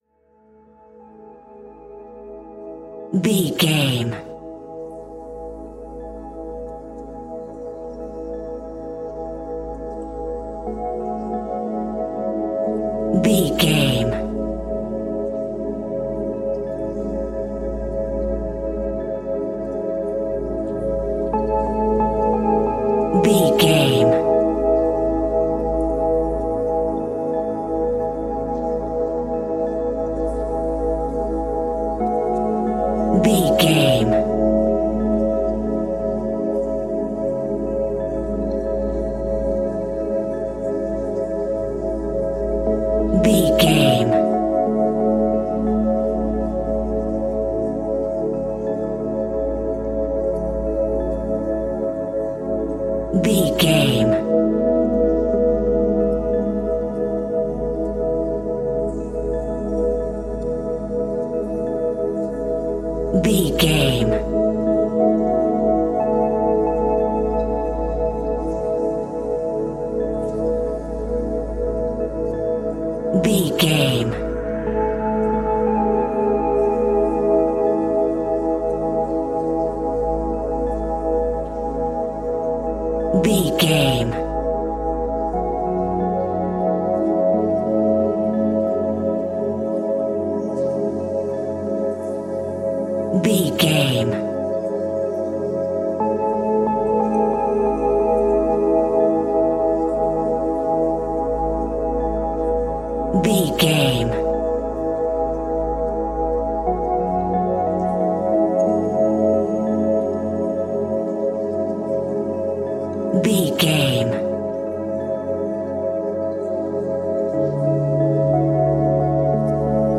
Ionian/Major
Slow
calm
ambient
ethereal
cinematic
meditative
dreamy
mellow
synthesiser